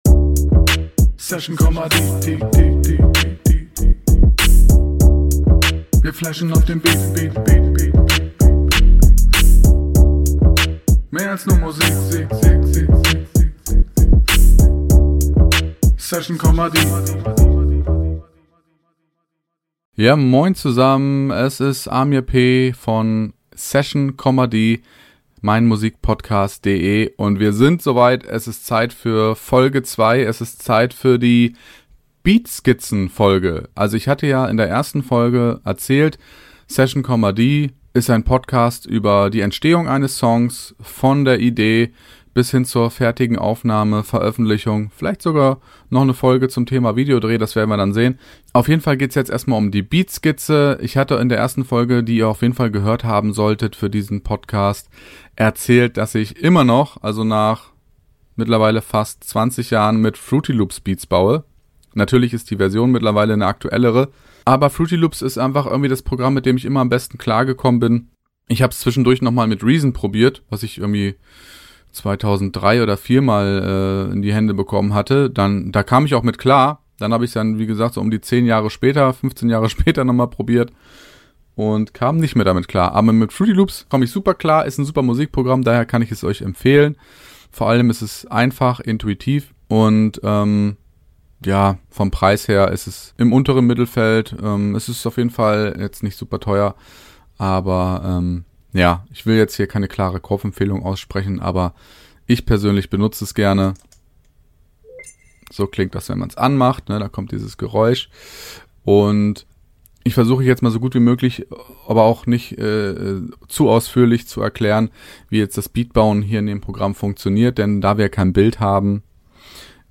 Sprich: Ein Projekt wird erstellt, Drum Samples werden ausgesucht und zu einem Loop zusammengebaut. Außerdem suche ich mir einen (vorläufigen) Bass-Sound im Internet und "zerhacke" ihn. Dabei plaudere ich ein bisschen über's Sampling.